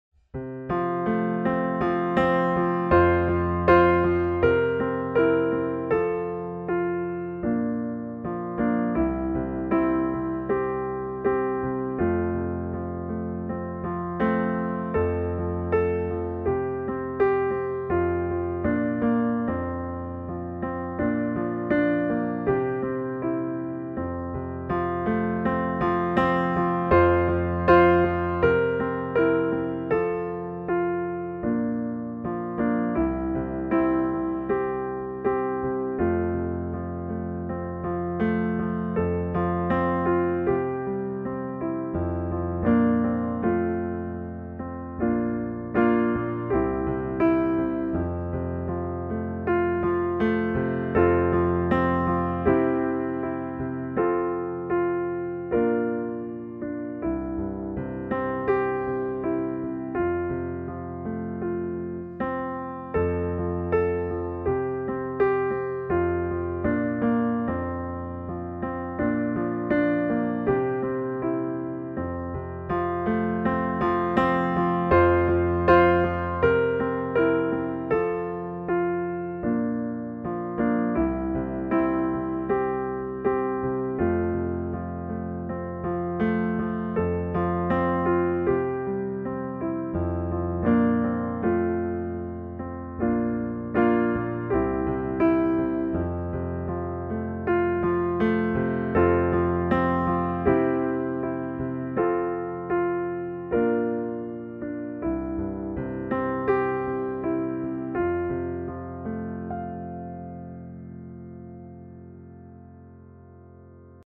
Nivå: Nybörjare